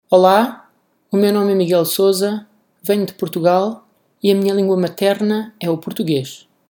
For all those who do not read IPA transcriptions fluently, there are some audio recordings: Whenever you see a ? symbol next to a name, you can click on it to listen to the speaker’s own pronunciation of their name. Most speakers say something along the lines of ‘Hi, my name is […], I come from […] and my mother tongue is […]’ – all that in (one of) their native language(s).